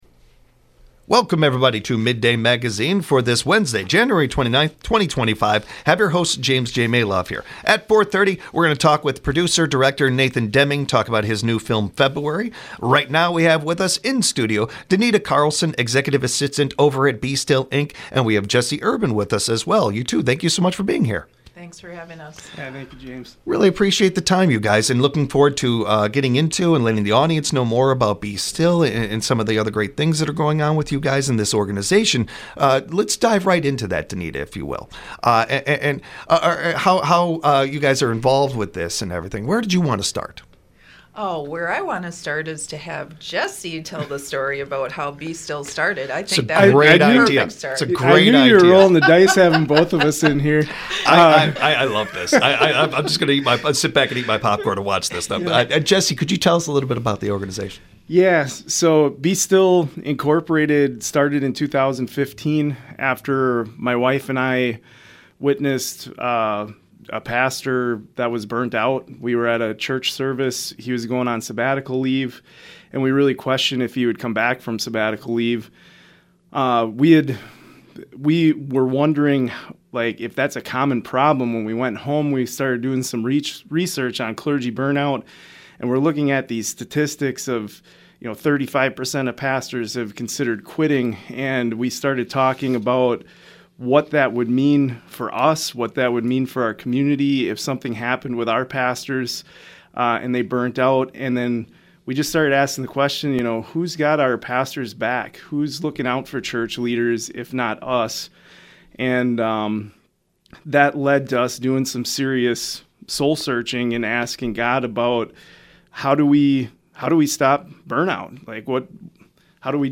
Mid-day Magazine gives you a first look into what’s happening in the Central Wisconsin area. WFHR has a variety of guests such as non-profit organizations, local officials, state representatives, event coordinators, and entrepreneurs.